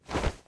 落地zth700521.wav
WAV · 21 KB · 單聲道 (1ch)
通用动作/01人物/01移动状态/落地zth700521.wav